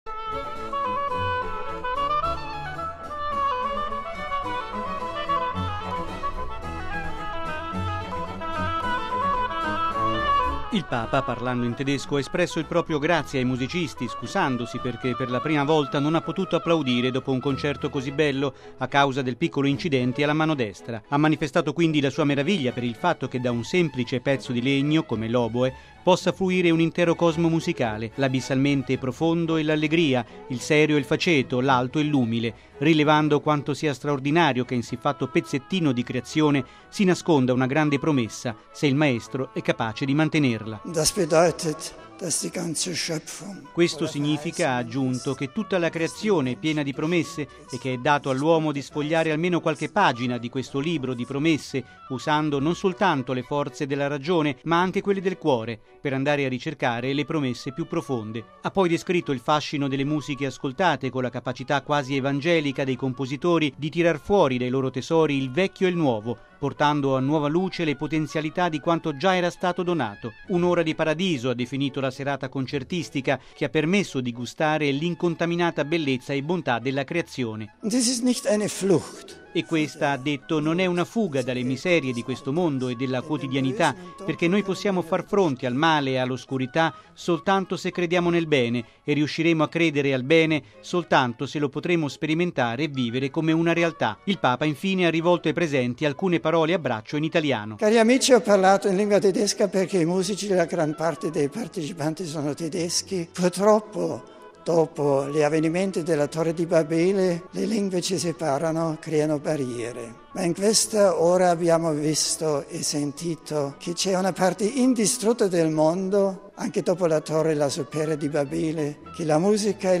◊   La bontà e la bellezza della Creazione di Dio sconfiggeranno le devastazioni del mondo: è quanto ha detto ieri sera il Papa al termine del concerto eseguito in suo onore, nel Cortile interno del Palazzo Apostolico di Castel Gandolfo, dall’Orchestra da Camera bavarese di Bad Bruckenau diretta dall’oboista Albrecht Mayer.
(musica)
Il Papa infine ha rivolto ai presenti alcune parole a braccio in italiano: